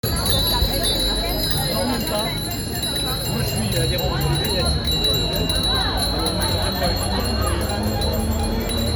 Cela faisait longtemps que je voulais être dans un cortège de vélos, je n'aurais jamais fait une distance comme celle-ci en étant aussi peu fatiguée à l'arrivée, ça m'apprendra à toujours foncer 🥵😅
j'ai trouvé ça incroyablement calme et mélodieux !
Je ne résiste d'ailleurs pas à l'envie de vous partager cette douce musique de sonnettes 🎶🛎🚲